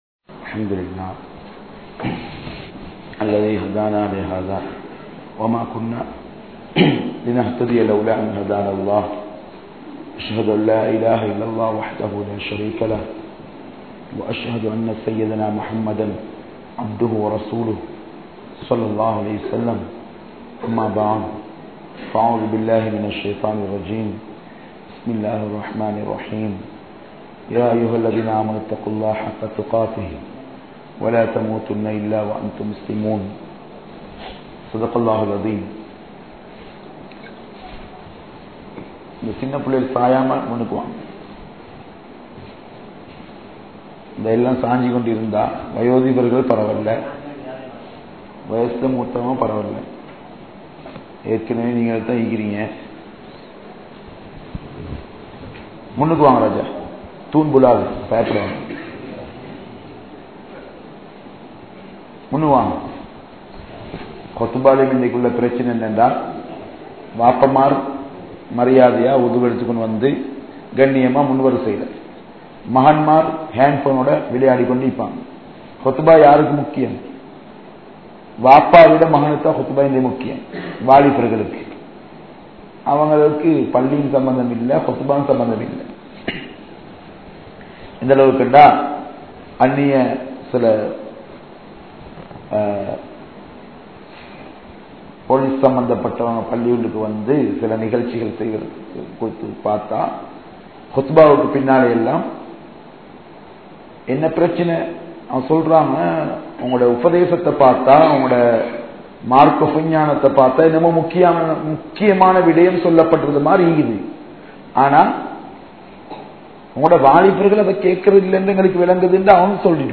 Thaqwa Illatha Pengalin Nilai (தக்வா இல்லாத பெண்களின் நிலை) | Audio Bayans | All Ceylon Muslim Youth Community | Addalaichenai
Kandy, Welamboda, Hidaya Jumua Masjidh